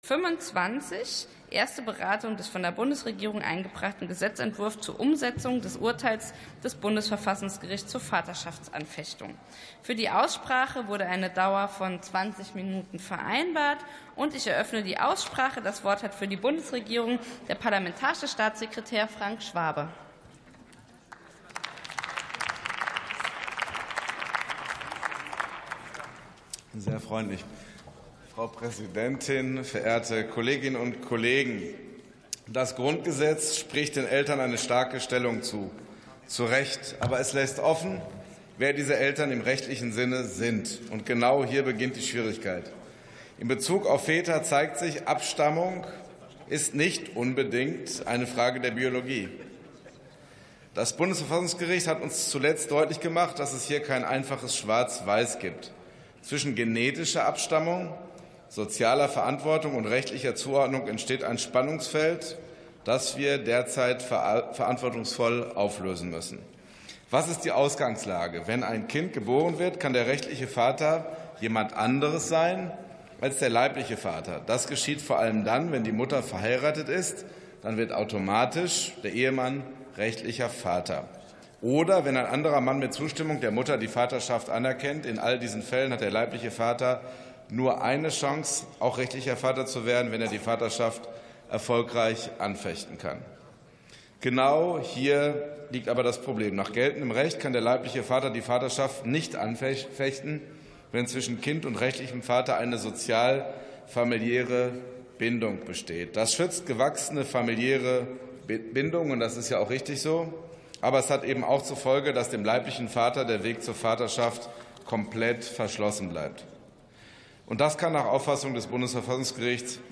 Sitzung vom 04.12.2025. TOP 25: Anpassung der Regelungen zur Vaterschaftsanfechtung ~ Plenarsitzungen - Audio Podcasts Podcast